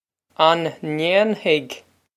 Pronunciation for how to say
On nain-hig?
This is an approximate phonetic pronunciation of the phrase.
This comes straight from our Bitesize Irish online course of Bitesize lessons.